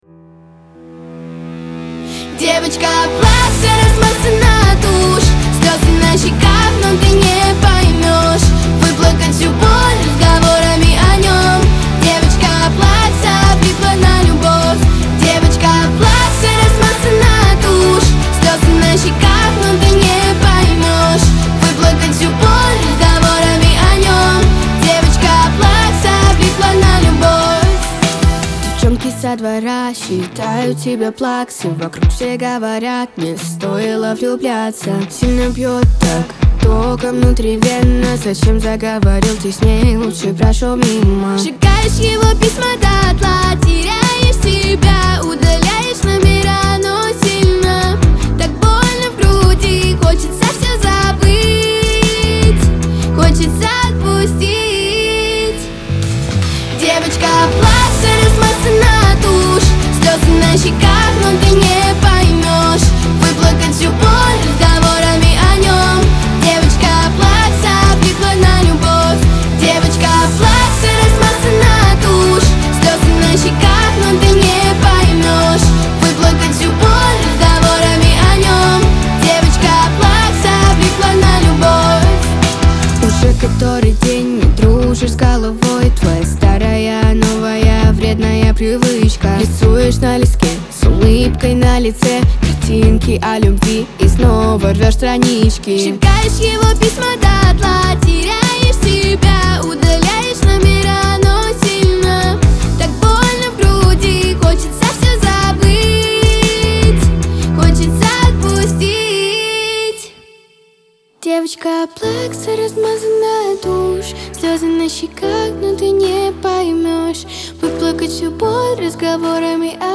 Трек размещён в разделе Русские песни / Альтернатива / 2022.